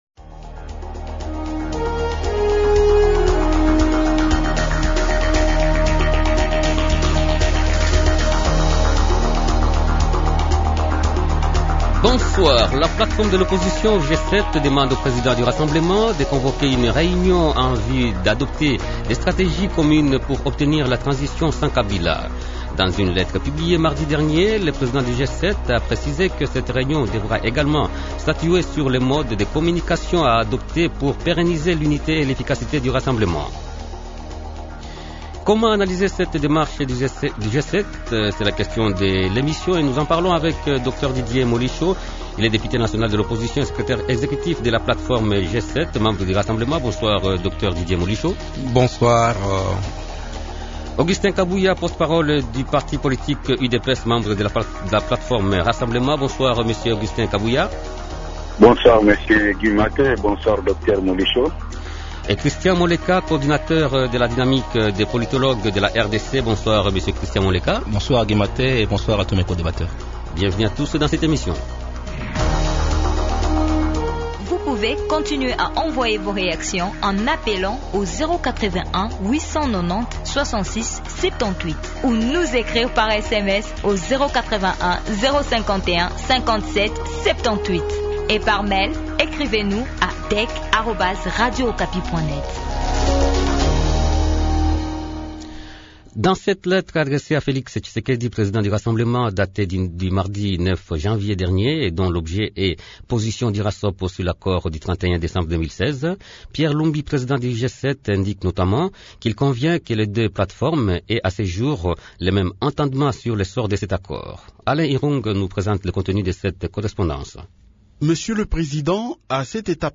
Invités -Dr. Didier Molisho, Député national de l’opposition et secrétaire exécutif de la plate-forme G7, membre du Rassemblement.